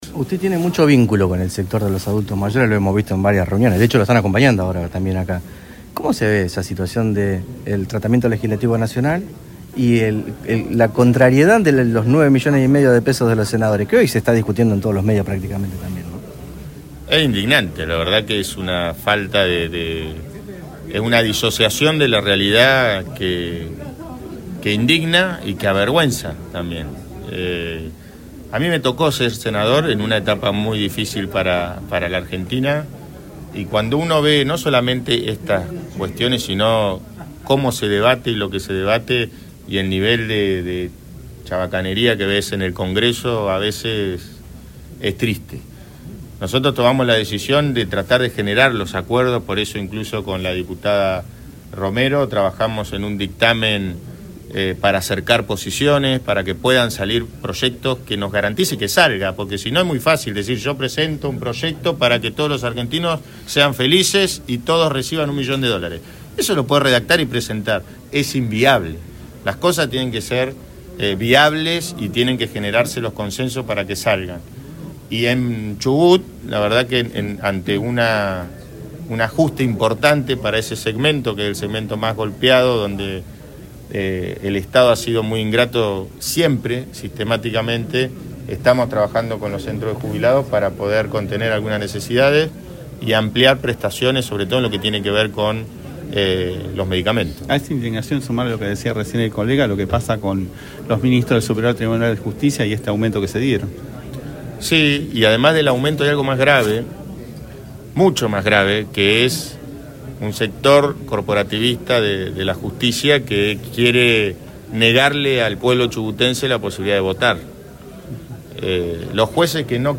En la conferencia de prensa de hoy el gobernador habló de todo